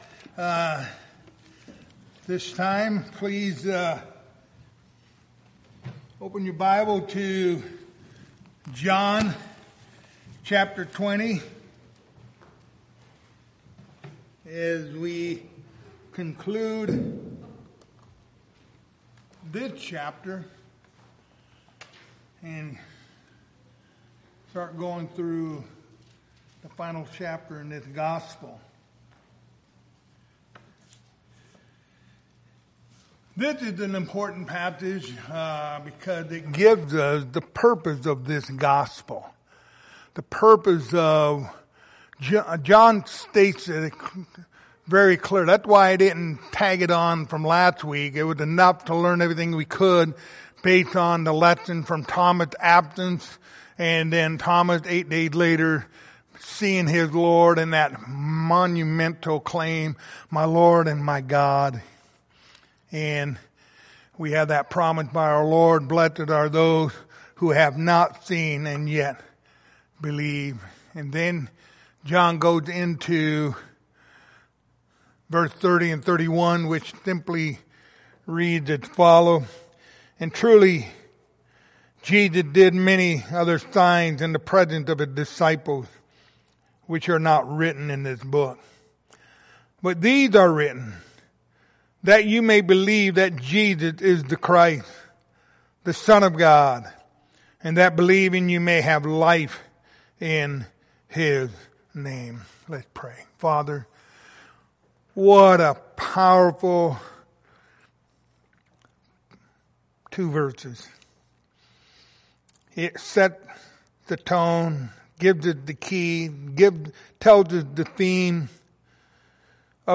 Series: The Gospel of John Passage: John 20:30-31 Service Type: Wednesday Evening